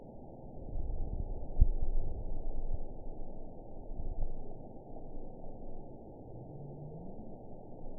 event 921941 date 12/23/24 time 04:06:46 GMT (5 months, 3 weeks ago) score 8.97 location TSS-AB03 detected by nrw target species NRW annotations +NRW Spectrogram: Frequency (kHz) vs. Time (s) audio not available .wav